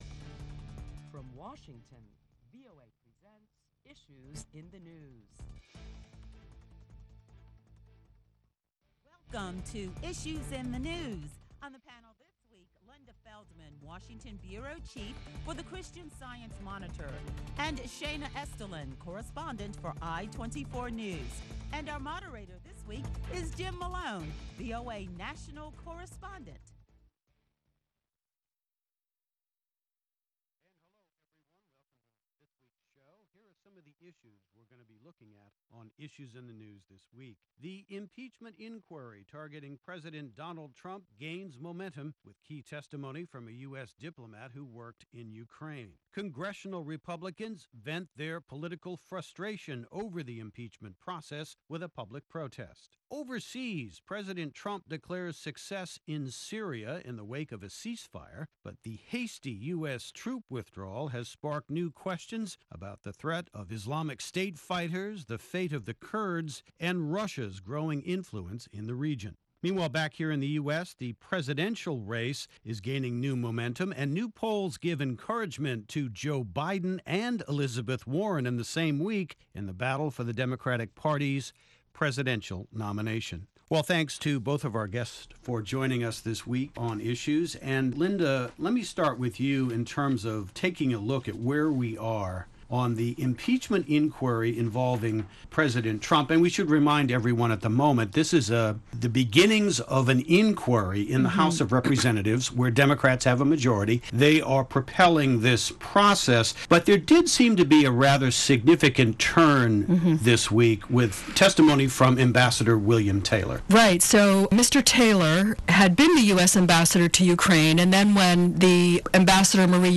Listen to a panel of prominent Washington journalists as they deliberate the latest top stories that include…the US Department of Justice opens a criminal investigation into the Russia probe.